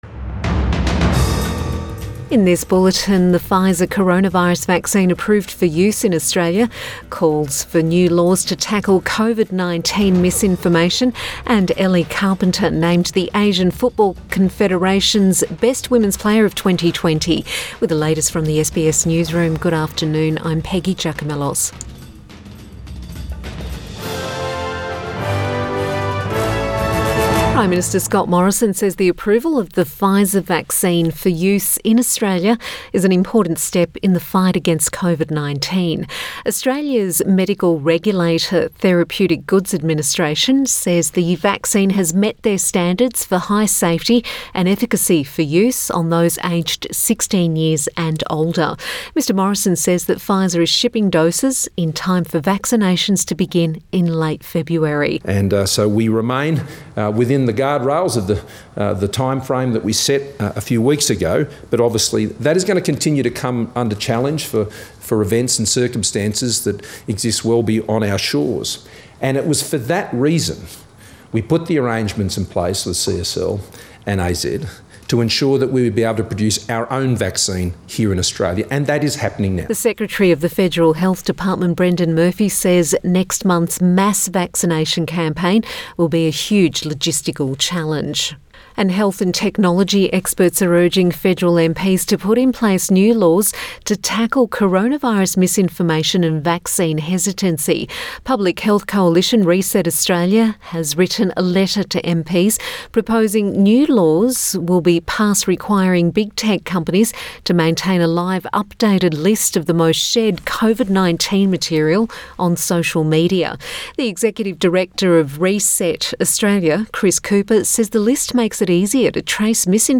Midday bulletin 25 January 2021